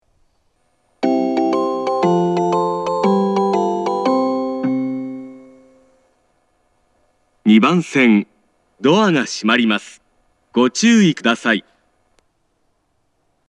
発車メロディー
こちらも一度扱えばフルコーラス鳴ります。
交換を行う場合は稀に混線することがあります。